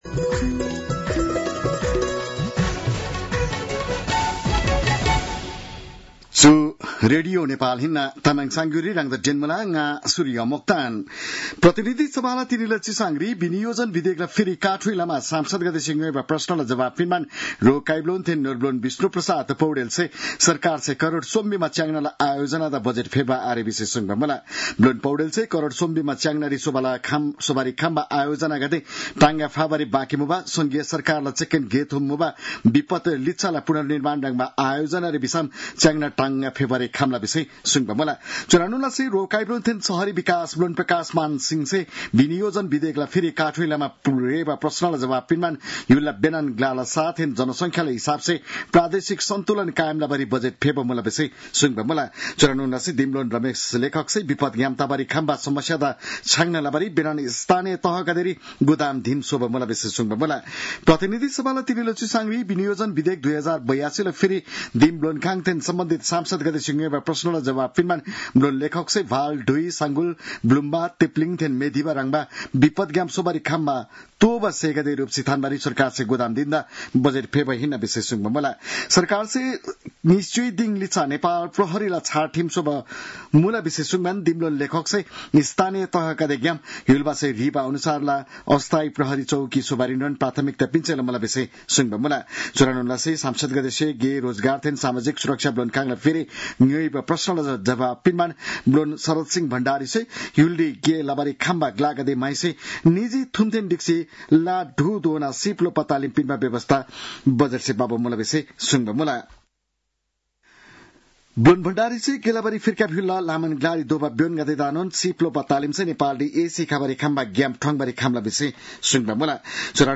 तामाङ भाषाको समाचार : १० असार , २०८२